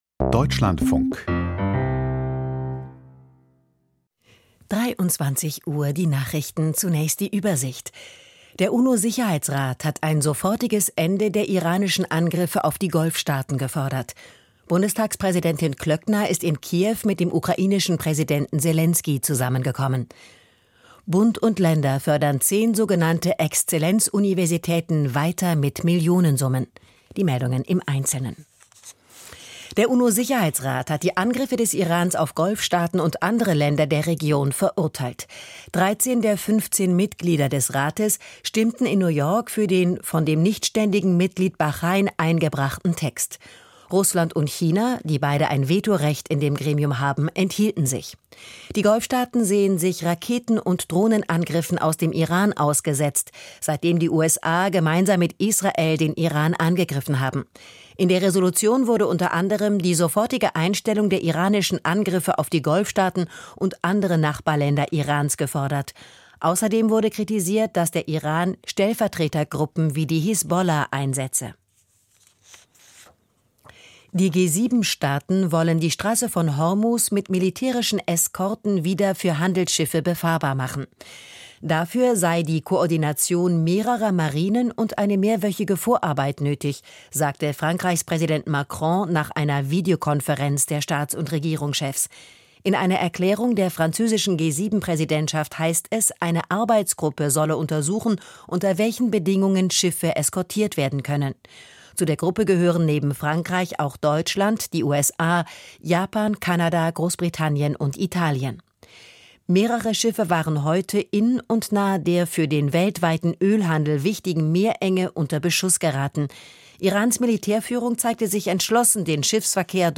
Die Nachrichten vom 11.03.2026, 23:00 Uhr